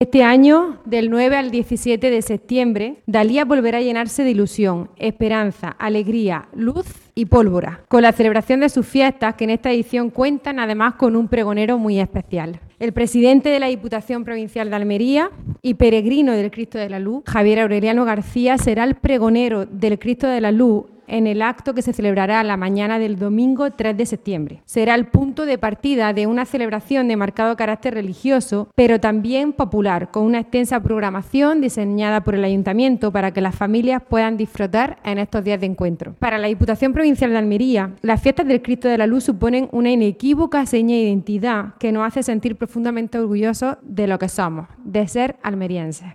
Esta mañana la Diputación Provincial de Almería ha acogido la presentación de esta arraigada tradición declarada de Interés Turístico Nacional de Andalucía
28-08_dalias_diputada.mp3